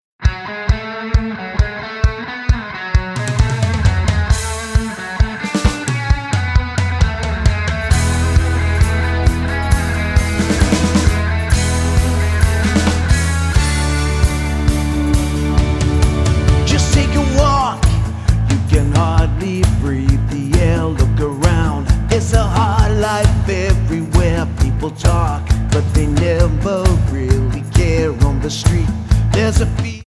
Tonart:A Multifile (kein Sofortdownload.
Die besten Playbacks Instrumentals und Karaoke Versionen .